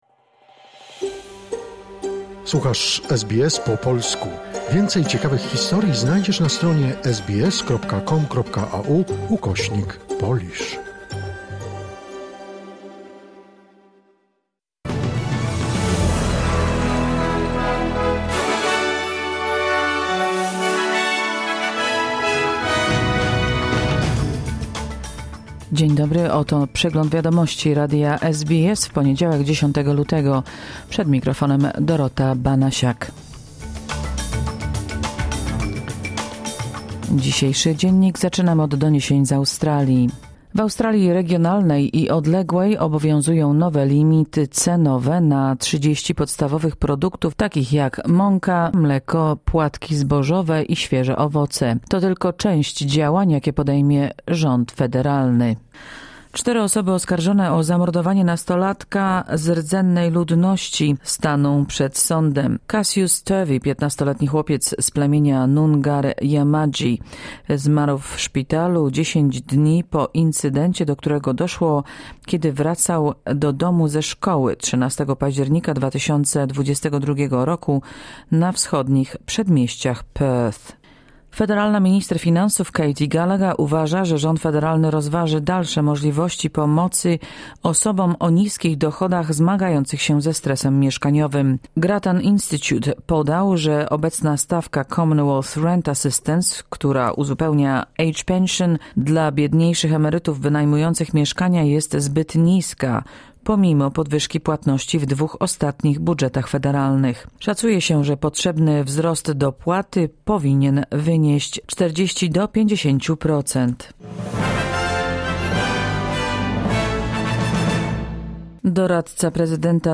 Wiadomości 10 lutego SBS News Flash